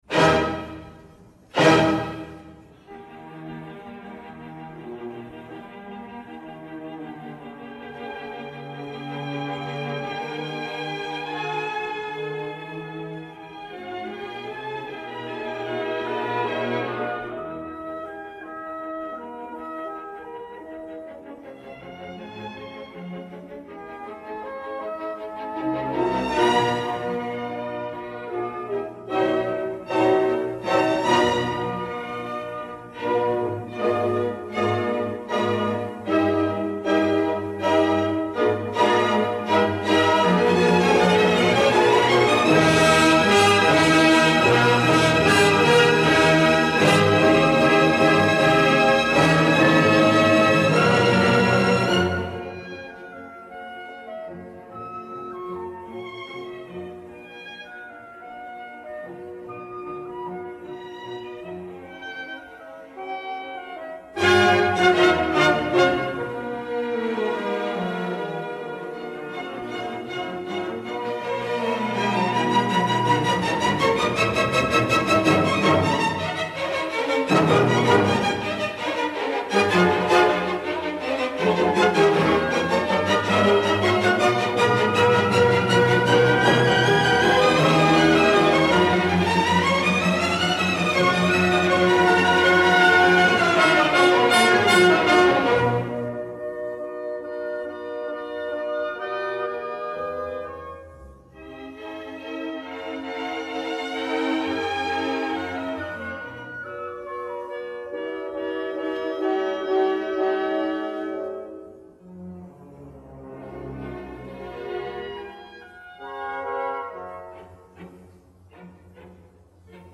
Musicalbox